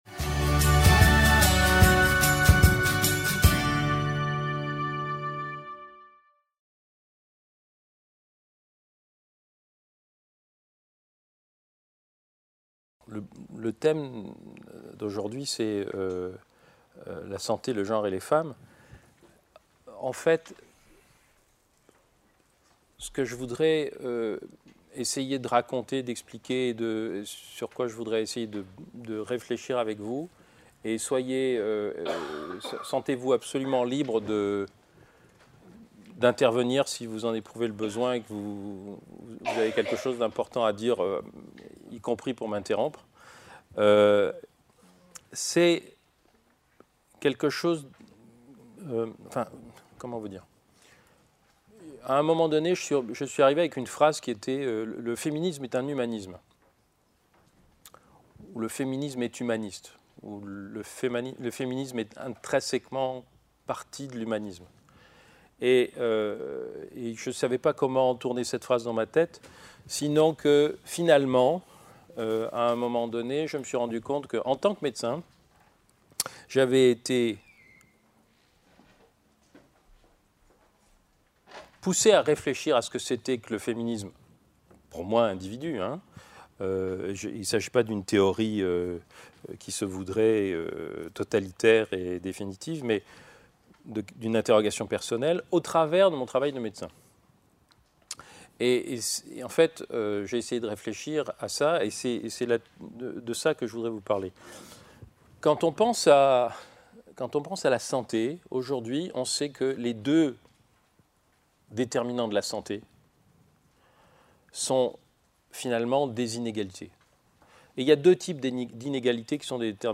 Le Laboratoire BABEL en collaboration avec la Culture scientifique et technique (CSTI) de l’Université vous présente une conférence sur le thème : Rencontre avec Martin Winckler Cette conférence a eu lieu le Mardi 15 mars à 17h, sur le Campus de La Garde à l’UFR Lettres et Sciences Humaines. Martin Winckler est médecin et écrivain.